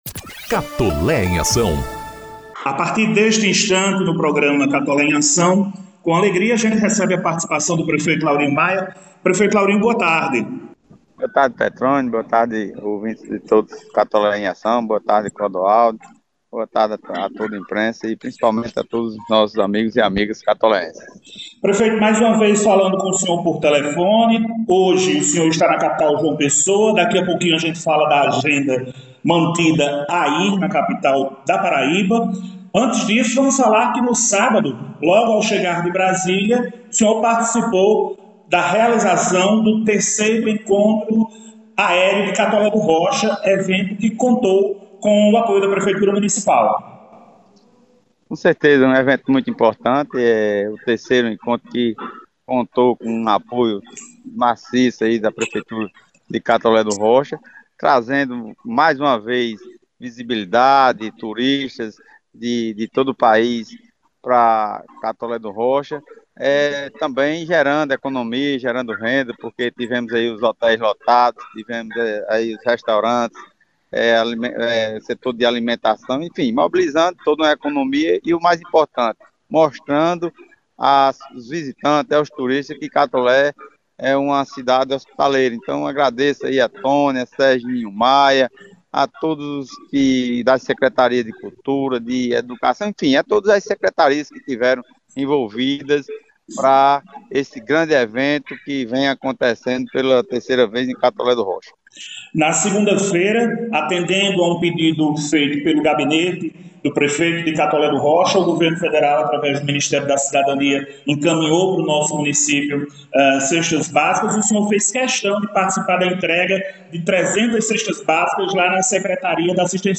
As informações foram divulgadas no programa institucional “Catolé em Ação”, edição n° 69, que entrevistou o prefeito, por telefone.